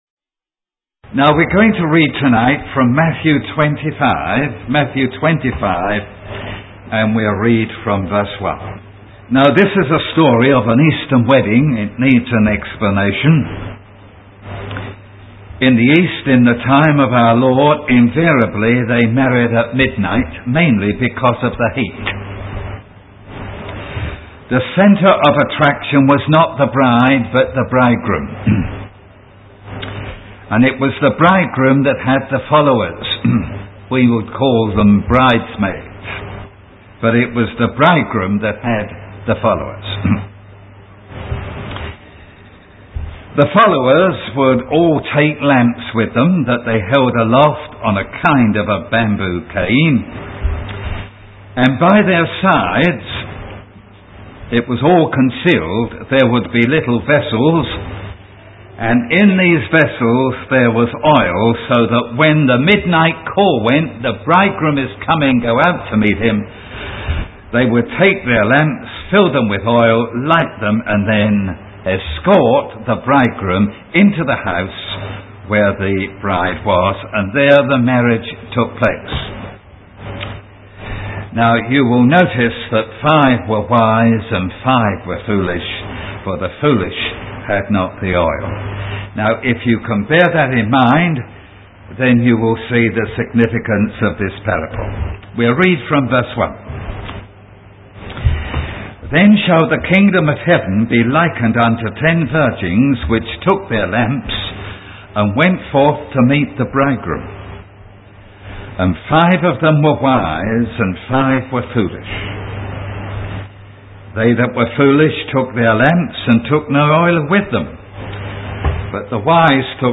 The sermon concludes with a call to action, encouraging listeners to seek the Lord while He may be found.